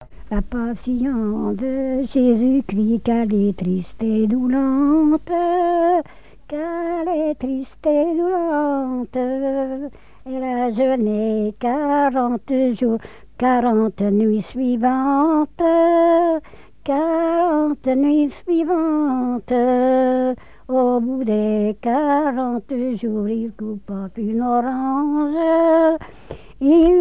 circonstance : quête calendaire
Genre strophique
répertoire de chansons, et d'airs à danser
Pièce musicale inédite